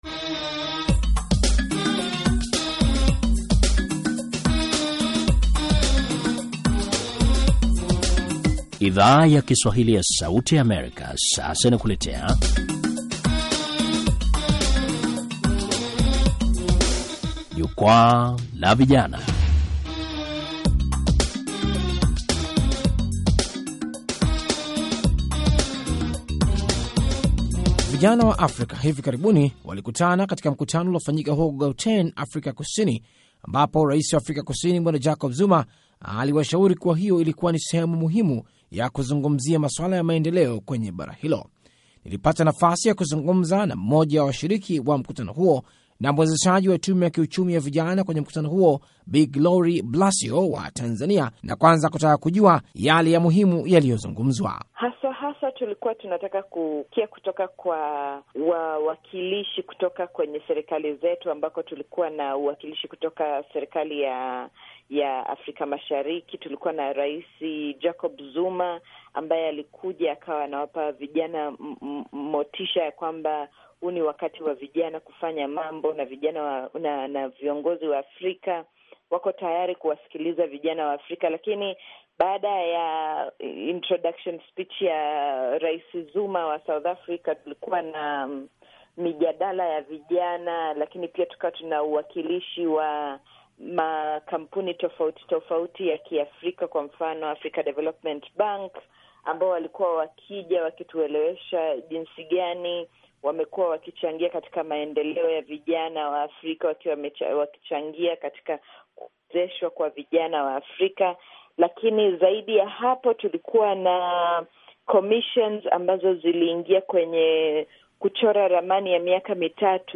Mazungumzo